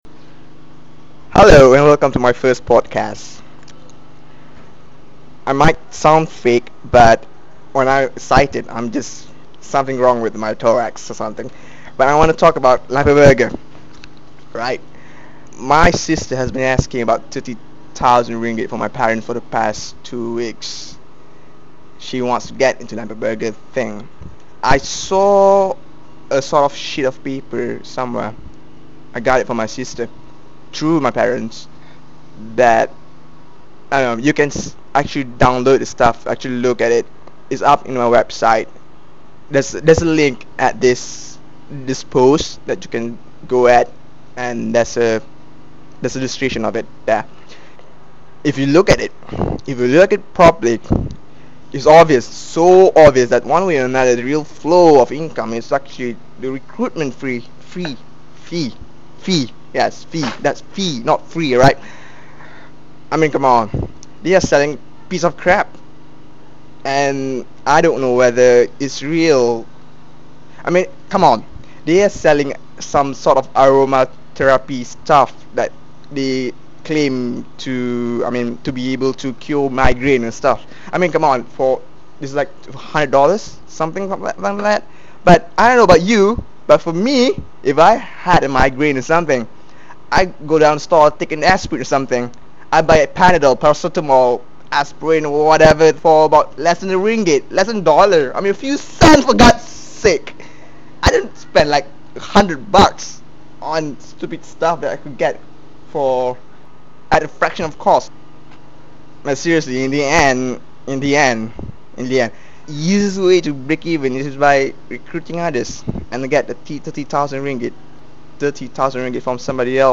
Well, technically, Zencast because I used my Zen Micro. For clarity, refer to [761] Of the reason why this Lampe Berger fad is a pyramid scheme, posted yesterday.